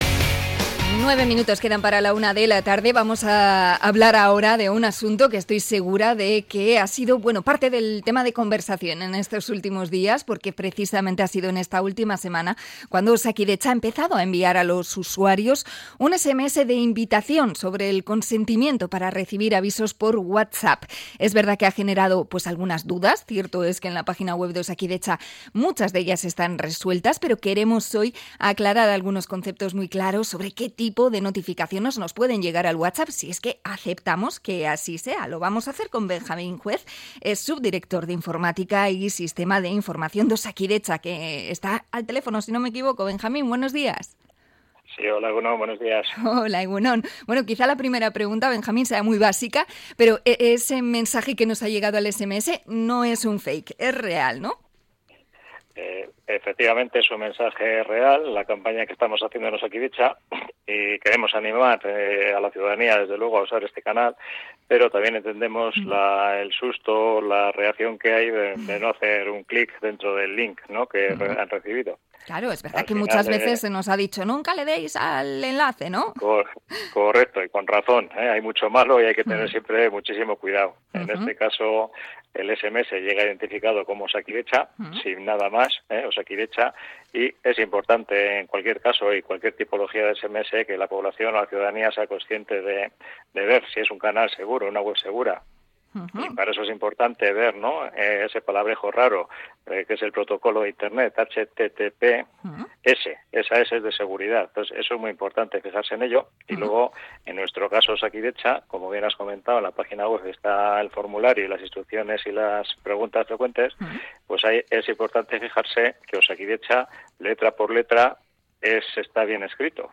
Entrevista a Osakidetza por el consentimiento para envíos a Whatsapp